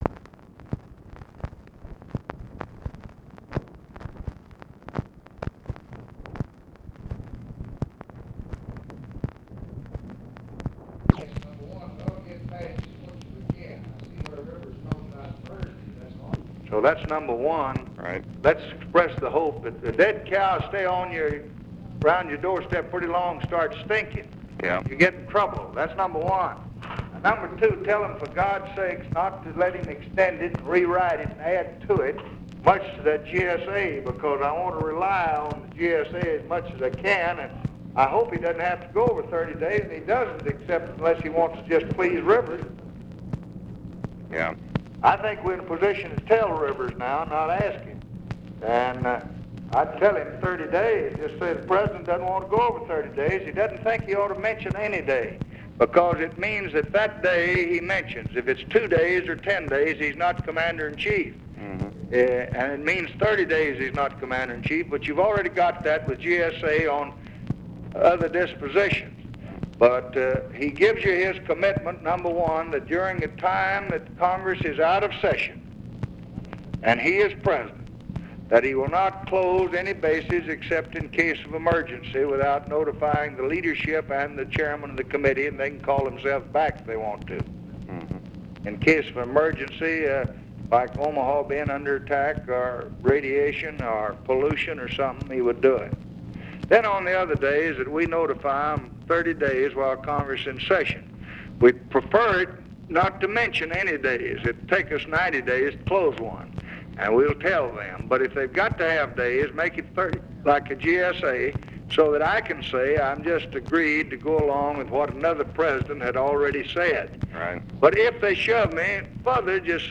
Conversation with ROBERT MCNAMARA, August 23, 1965
Secret White House Tapes